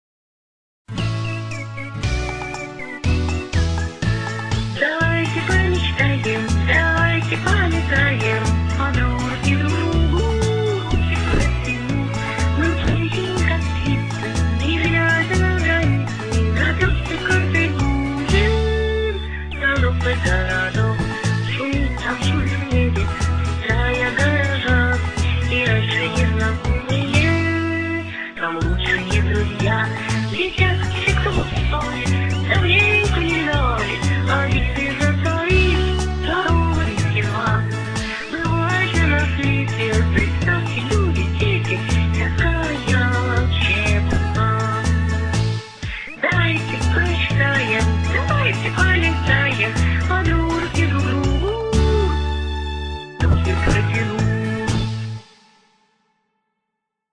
• Жанр: Детские песни
Песенка горожан